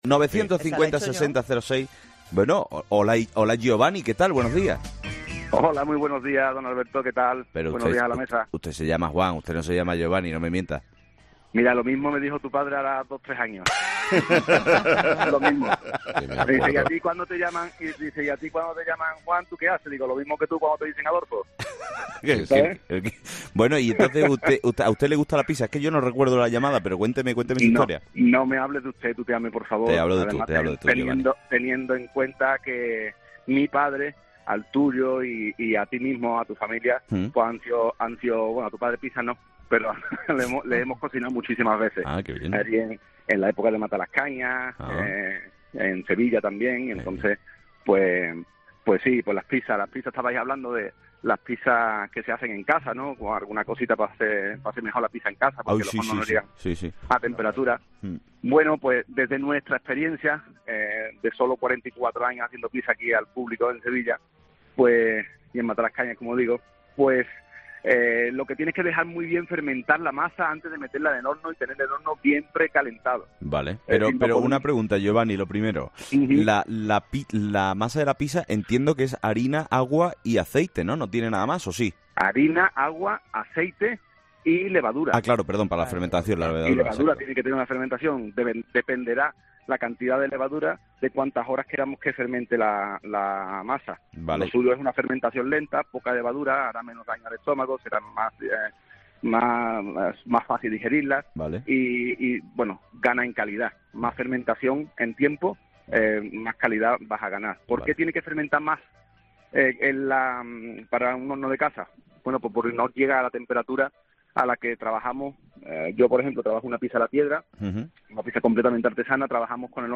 Estas son algunas de las preguntas que hemos lanzado en esta ocasión a nuestros fósforos en 'Herrera en COPE'.